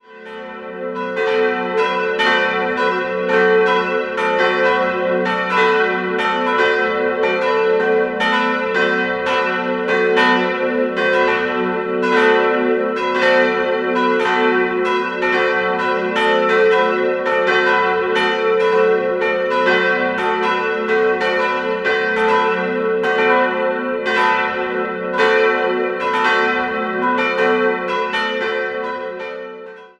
Der Turm der Pfarrkirche ist noch mittelalterlich, das Langhaus jedoch wurde 1907 vom Münchner Architekten Gabriel von Seidl errichtet. 3-stimmiges Geläut: fis'-a'-h' Die Glocken stammen von Karl Czudnochowsky aus dem Jahr 1952.